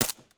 sounds / weapons / _bolt / smg45_2.ogg
smg45_2.ogg